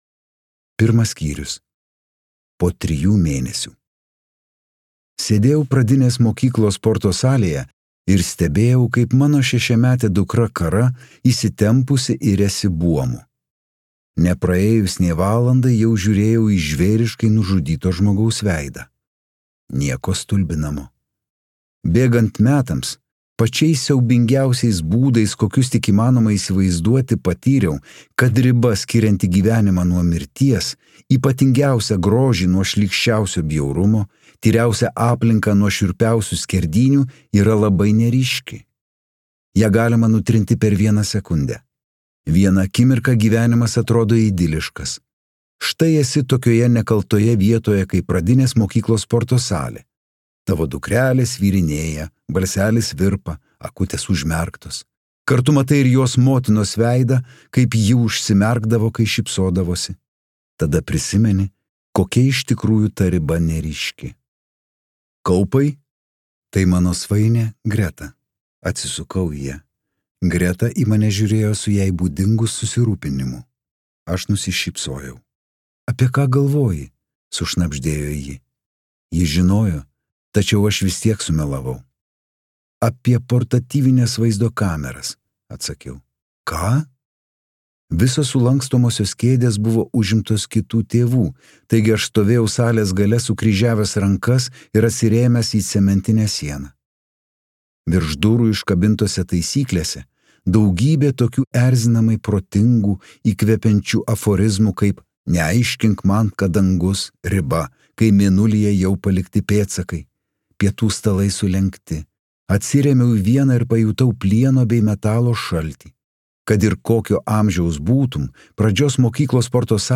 Miškai | Audioknygos | baltos lankos